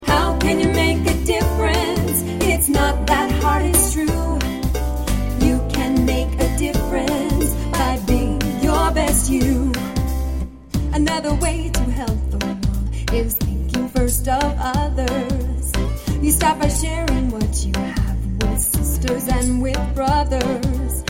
song clip